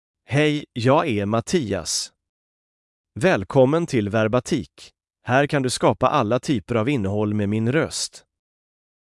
MaleSwedish (Sweden)
MattiasMale Swedish AI voice
Voice sample
Male
Mattias delivers clear pronunciation with authentic Sweden Swedish intonation, making your content sound professionally produced.